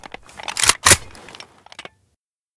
aks74u_unjam.ogg